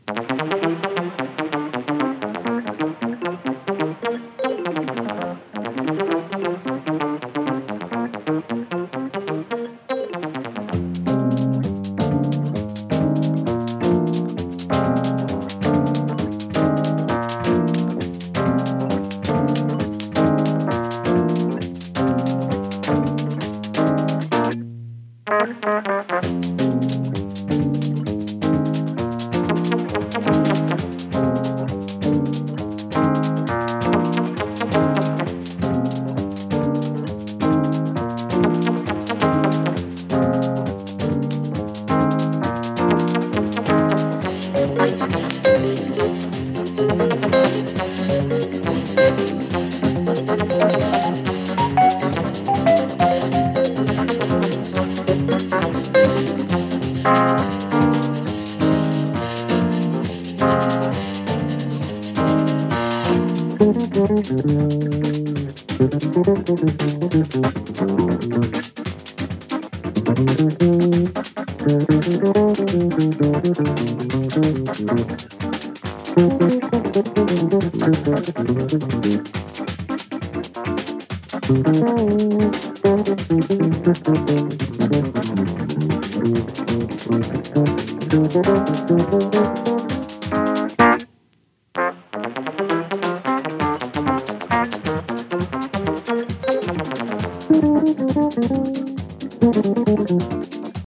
東欧ジャズ 疾走 コズミック フュージョン